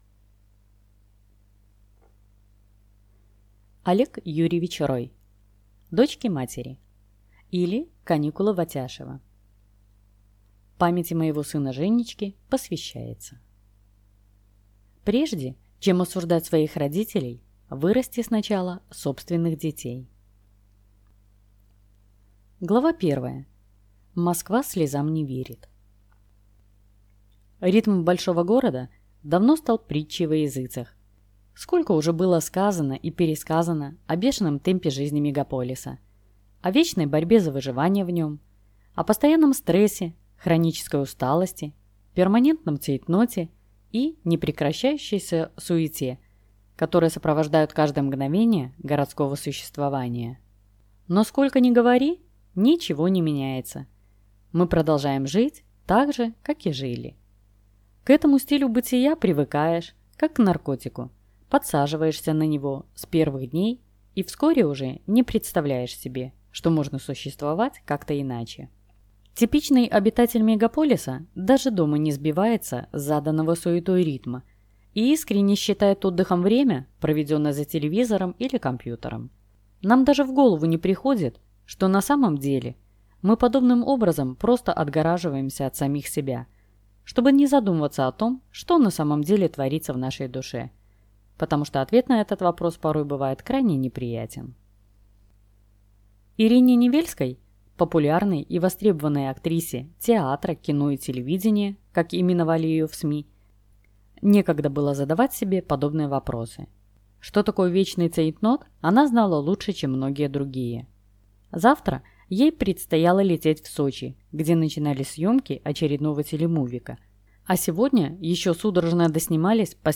Аудиокнига Дочки-матери, или Каникулы в Атяшево | Библиотека аудиокниг